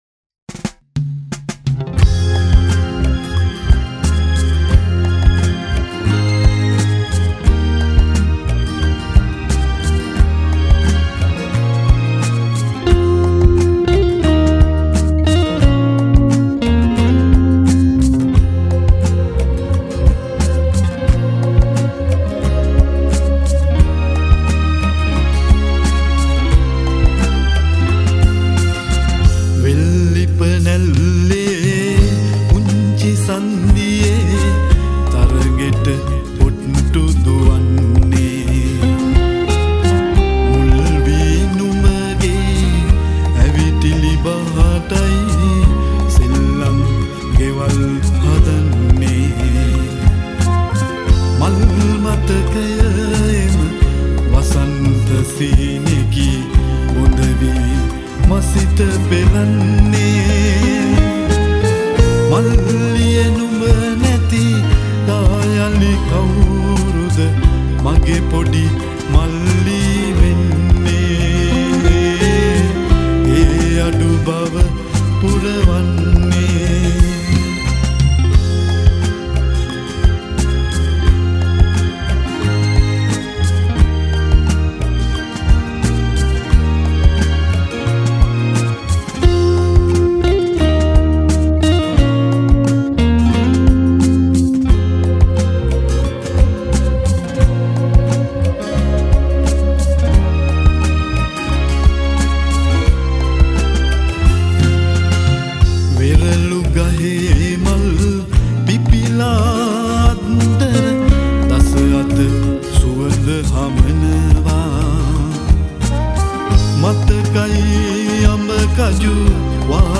at Sumeega Studio Panadura Sri Lanka